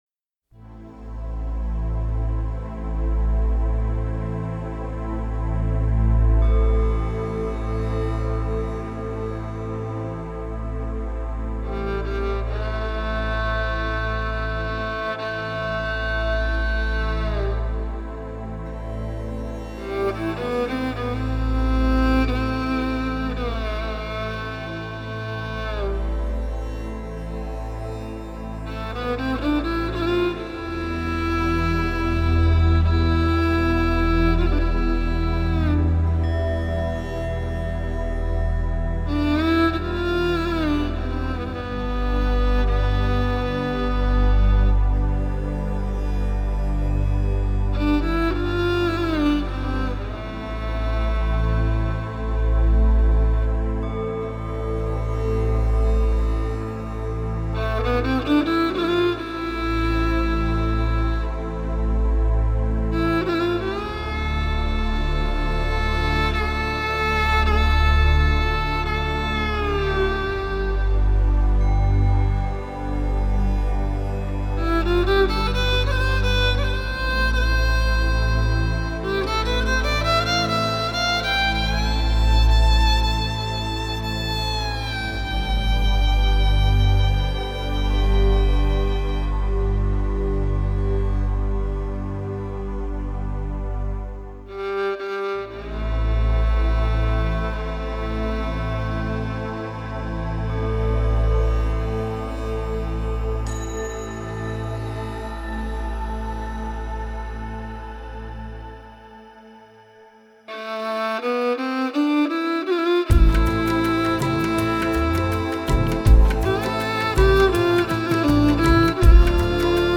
Жанр: Relax